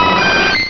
chansey.wav